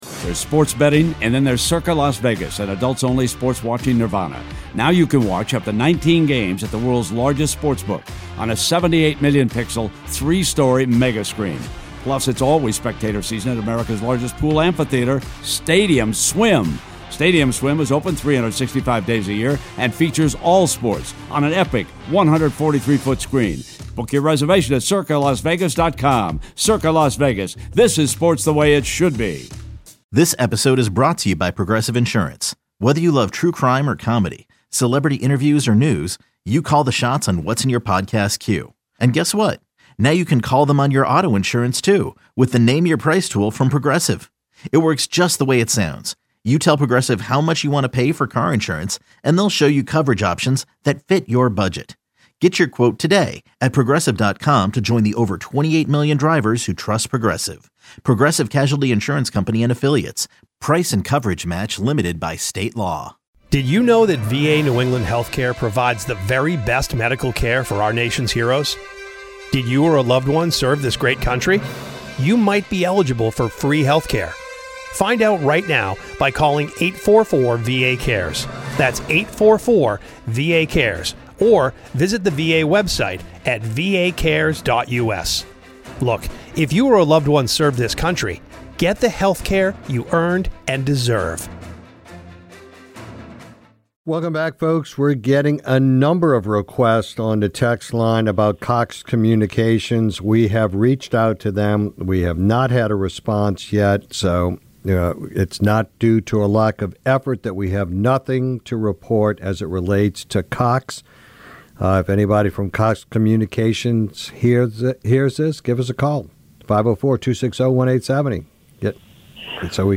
Newell interviewed Louisiana Lieutenant Governor Billy Nungesser about the aftermath of Hurricane Francine.